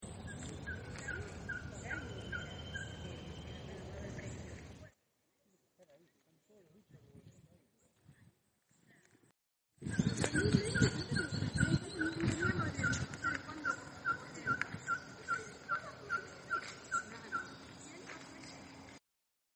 Ferruginous Pygmy Owl (Glaucidium brasilianum)
Life Stage: Adult
Location or protected area: Reserva Ecológica Costanera Sur (RECS)
Condition: Wild
Certainty: Recorded vocal